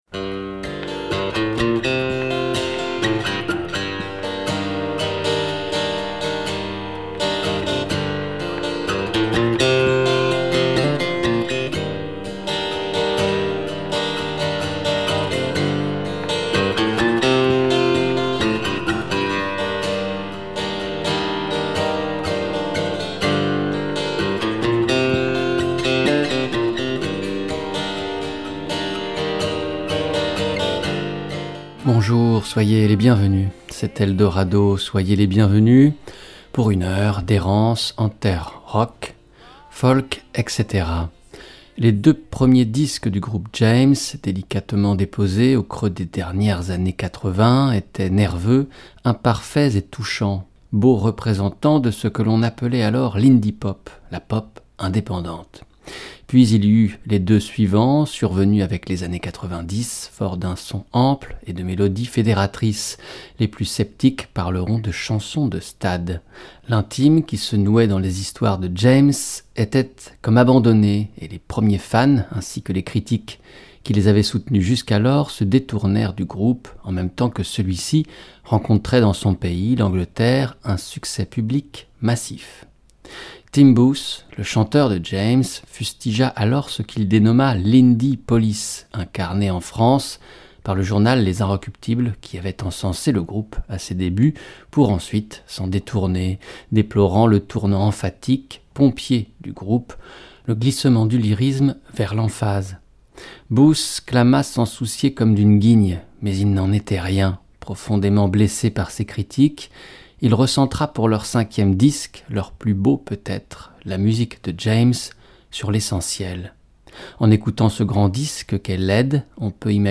Rock et Folk. D'un artiste à un autre, on retrace les parcours, les rencontres de chacun pour finir par comprendre comment les choses ont évolué.